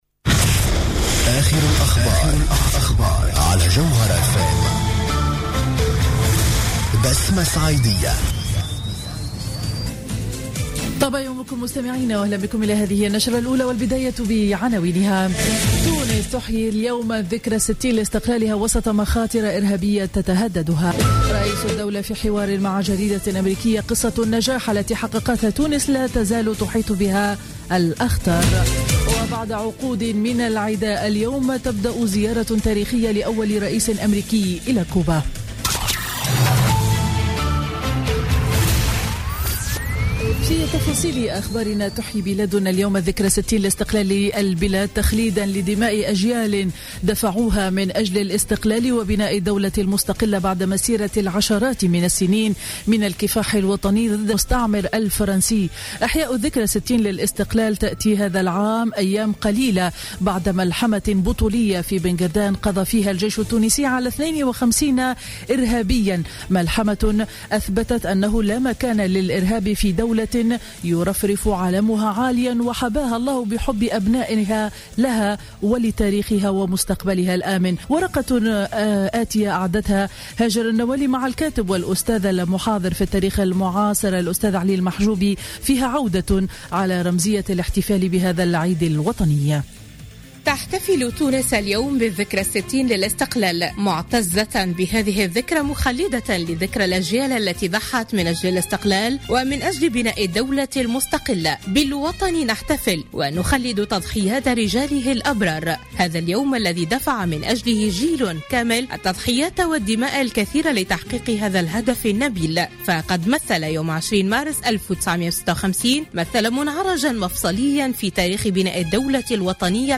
Journal Info 07h00 du Dimanche 20 Mars 2016